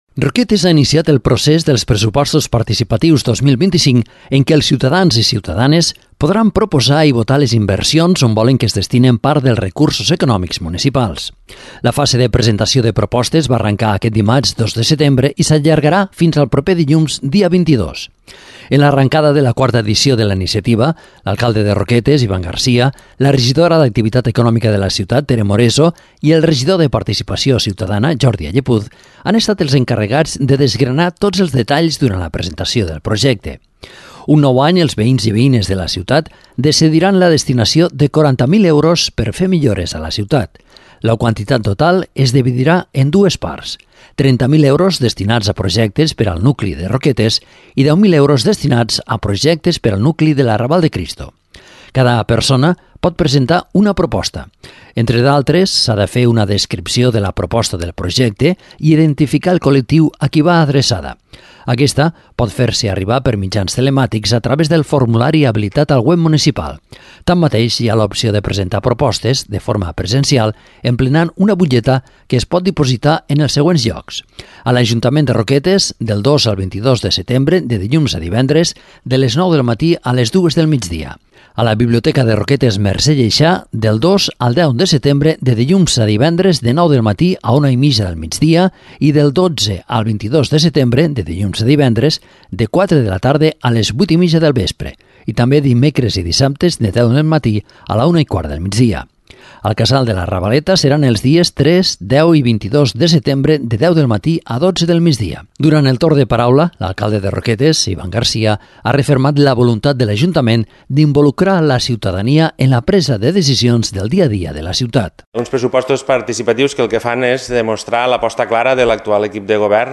En l’arrencada de la quarta edició de la iniciativa, l’alcalde de Roquetes, Ivan Garcia; la regidora d’Activitat Econòmica de la ciutat, Tere Moreso; i el regidor de Participació Ciutadana, Jordi Allepuz, han estat els encarregats de desgranar tots els detalls, durant la presentació del projecte.